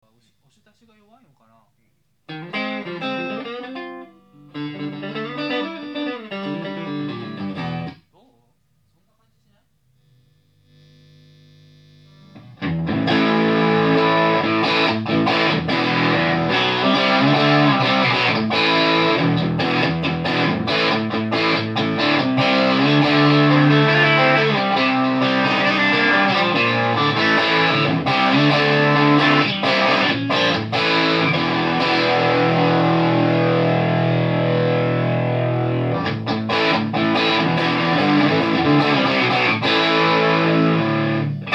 ただ、録音状態が悪く、メディアから拾うのに時間が掛
ProJr+Neo　普通。無難。